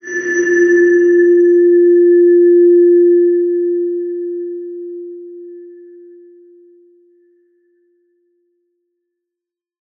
X_BasicBells-F2-pp.wav